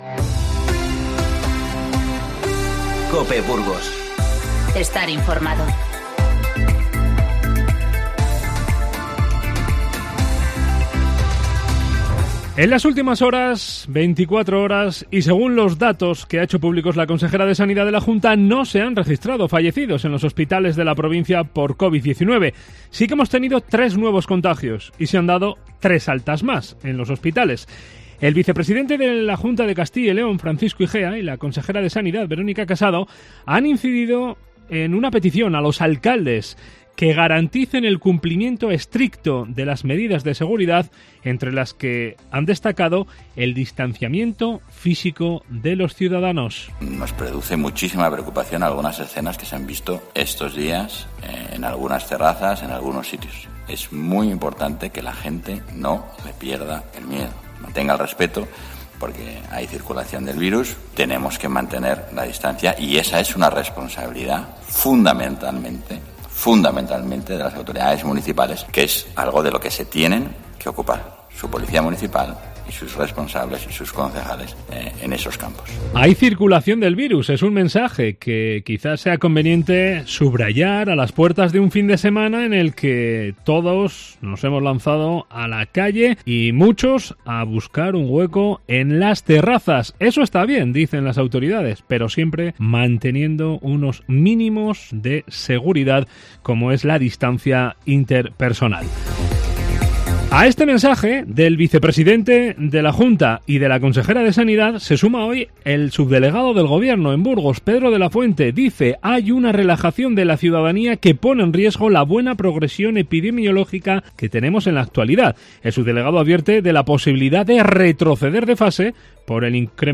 INFORMATIVO MEDIODÍA 29/05